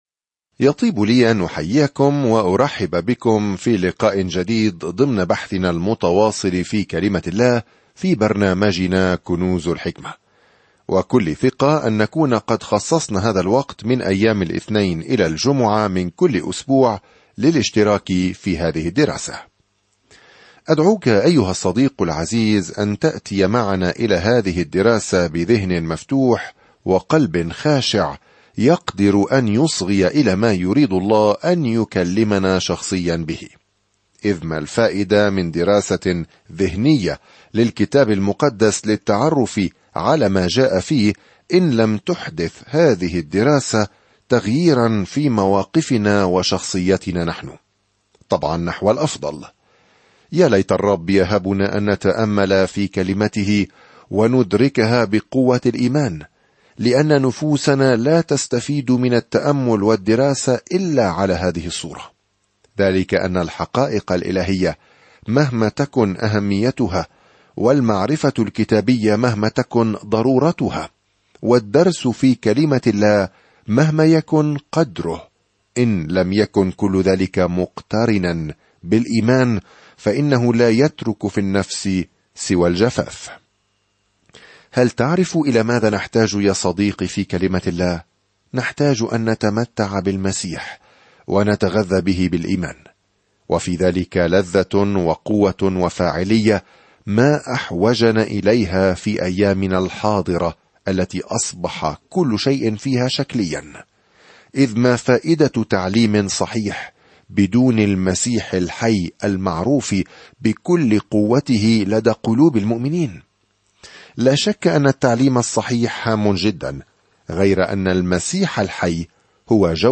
الكلمة نَاحُوم 11:1-15 يوم 3 ابدأ هذه الخطة يوم 5 عن هذه الخطة ناحوم، الذي يعني اسمه تعزية، يحمل رسالة دينونة لأعداء الله ويجلب العدل والرجاء لإسرائيل. سافر يوميًا عبر ناحوم وأنت تستمع إلى الدراسة الصوتية وتقرأ آيات مختارة من كلمة الله.